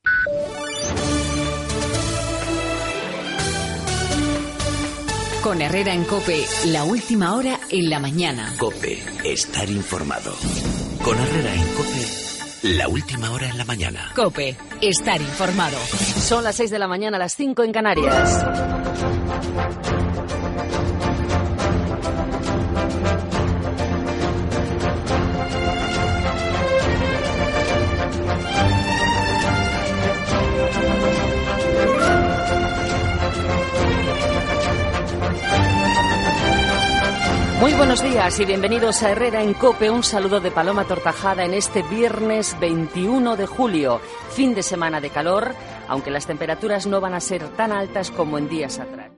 Careta del programa, hora, sintonia i presentació inicial
Info-entreteniment
FM